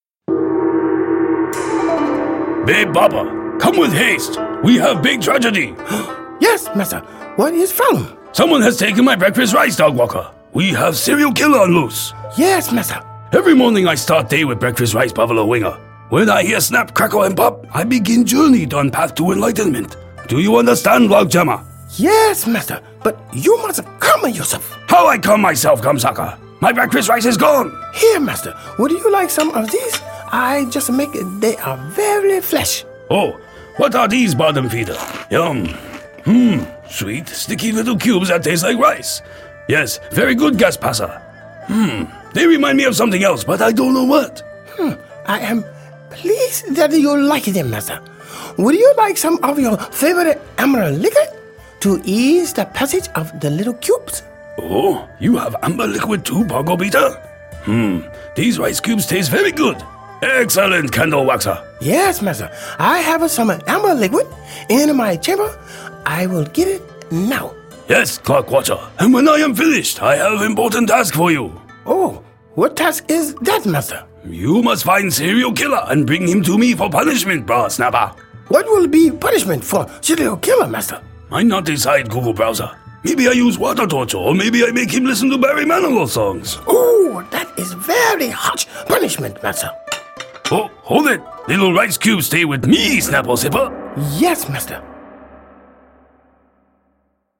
Hunker Down Hollow - Radio Comedy Shows by Canamera